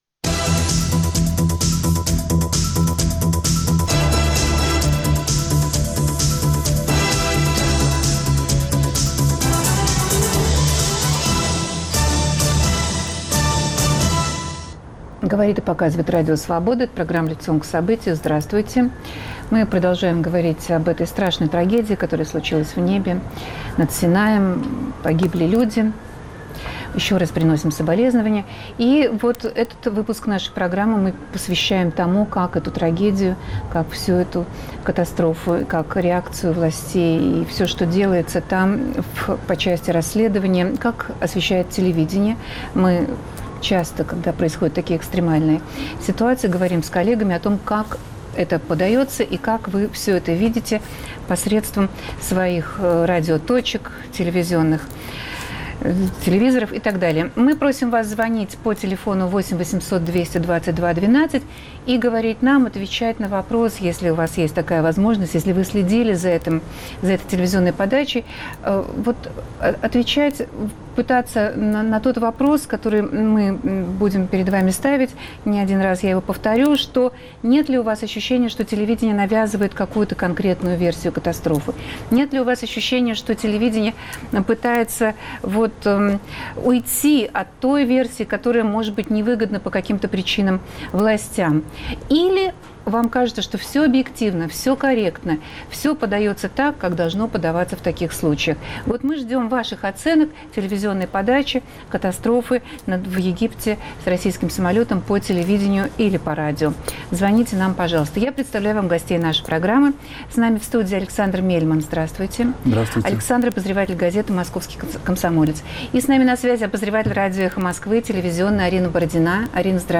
Существует ли версия крушения борта А321, которая по какой-то причине может навязываться общественному мнению? Либо телевидение освещает трагедию достойно и объективно. Анализируют телекритики